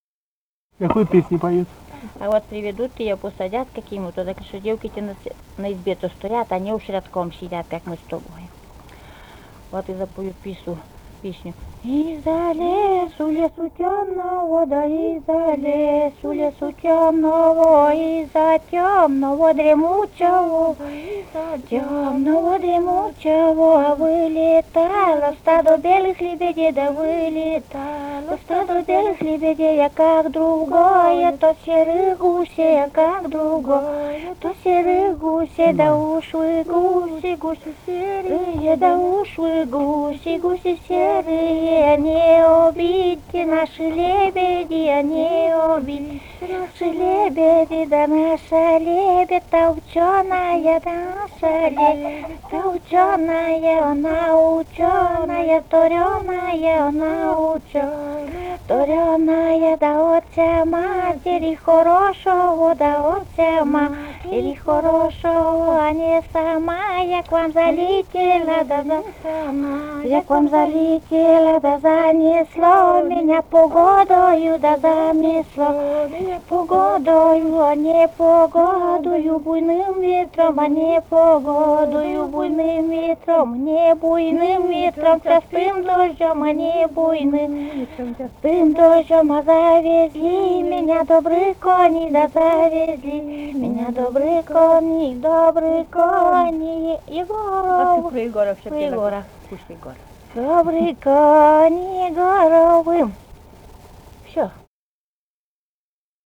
Этномузыкологические исследования и полевые материалы
«Из-за лесу, лесу тёмного» (свадебная).
Вологодская область, д. Усть-Вотча Марьинского с/с Вожегодского района, 1969 г. И1132-10